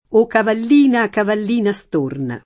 storno [St1rno] s. m. («uccello») — es.: storno nero; storno marino; uno stormo di storni — anche agg., riferito al mantello di cavalli («nero a macchie bianche»): O cavallina, cavallina storna [